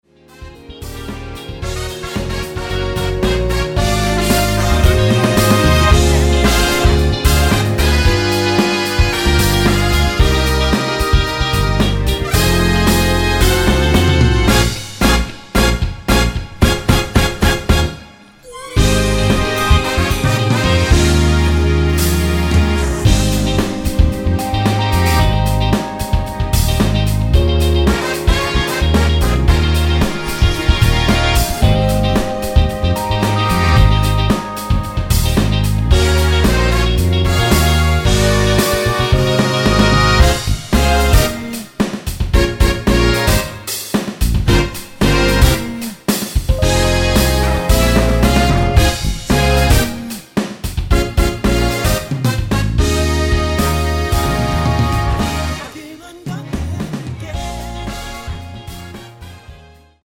원키 코러스 포함된 MR 입니다.(미리듣기 참조)
Ab
2만의 고퀄 MR 너무잘쓰겠습니다!!!
앞부분30초, 뒷부분30초씩 편집해서 올려 드리고 있습니다.
중간에 음이 끈어지고 다시 나오는 이유는